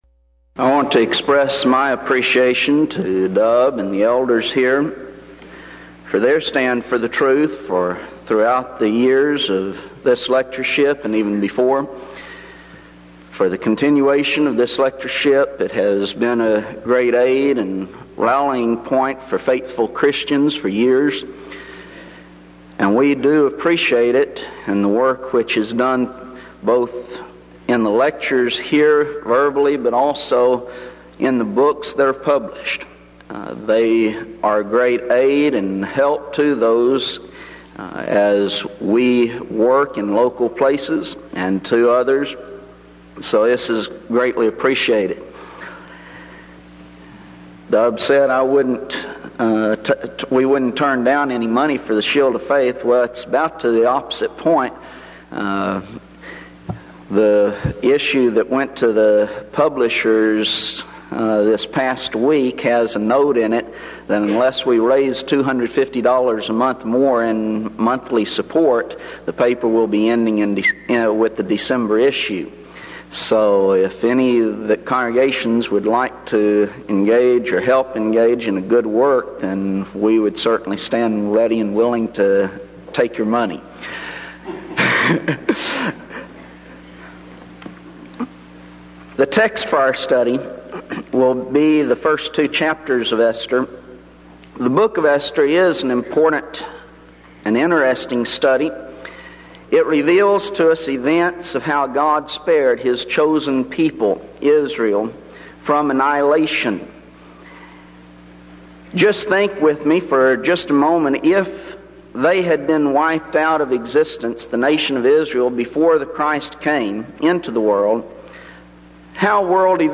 Event: 1992 Denton Lectures
lecture